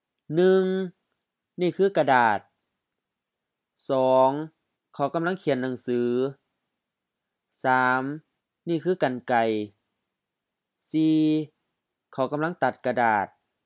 Notes: sentence-final: often with rising tone which is likely a Thai influence